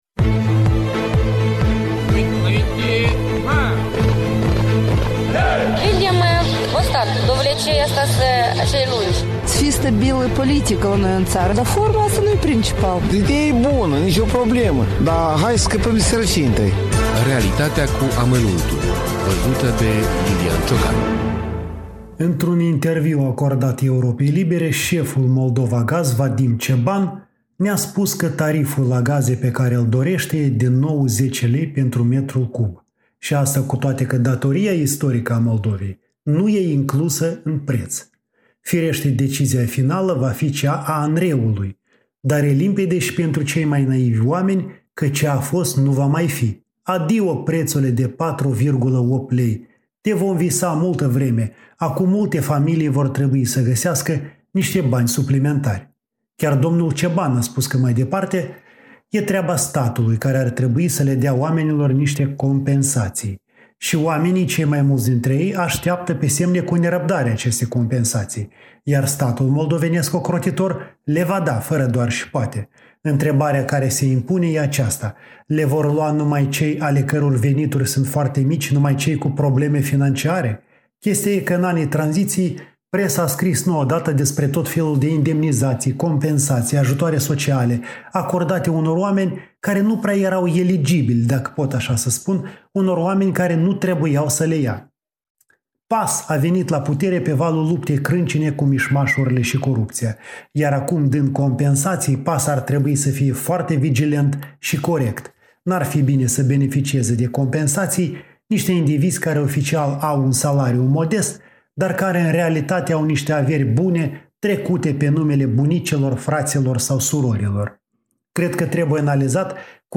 Noul preţ al gazelor şi beneficiarii compensaţiilor Într-un interviu acordat Europei Libere